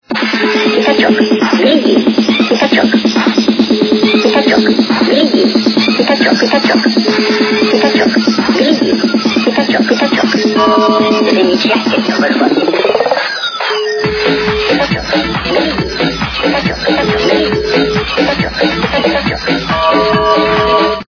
Люди фразы